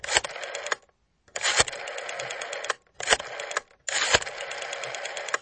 Add payphone sound files
old-payphone-dial.mp3